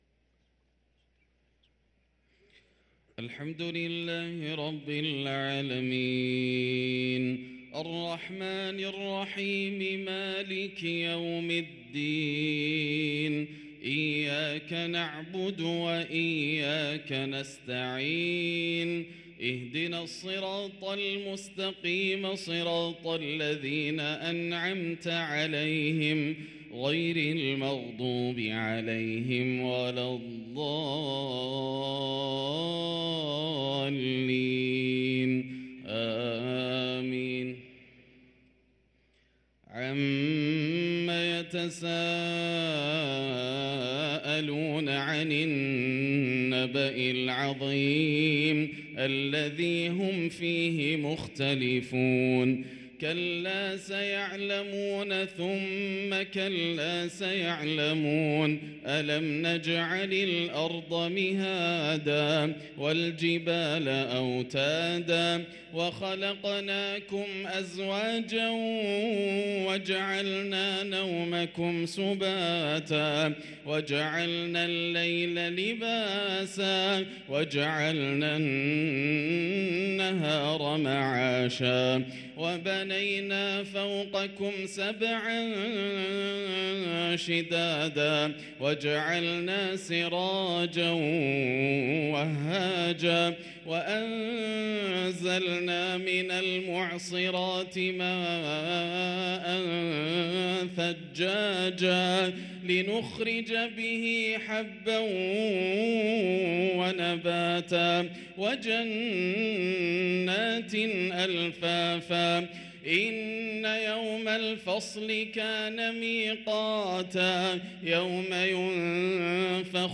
صلاة العشاء للقارئ ياسر الدوسري 20 جمادي الآخر 1444 هـ